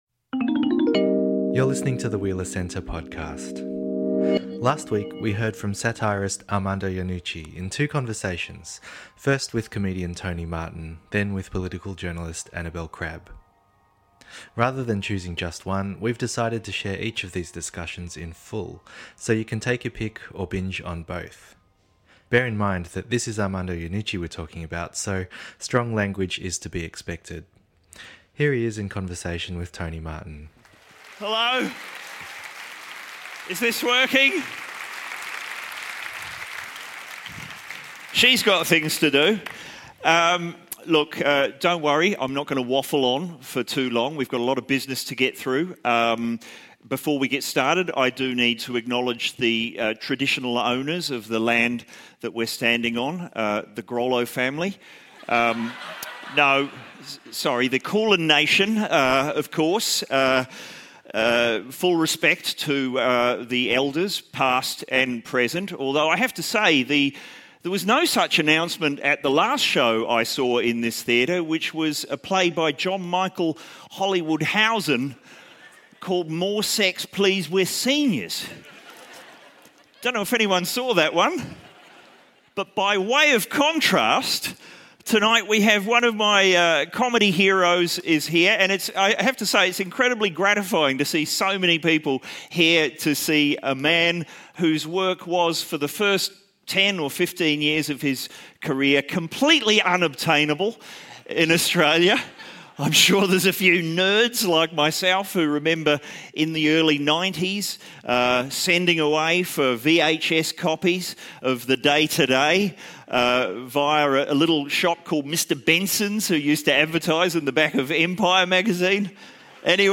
In conversation with Tony Martin, Iannucci discusses the predicaments and possibilities of political satire today. Tony Martin and Armando Iannucci at Melbourne's Comedy Theatre